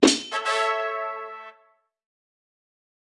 Media:BarbarianKing_evo2_dep.wav 部署音效 dep 在角色详情页面点击初级、经典、高手和顶尖形态选项卡触发的音效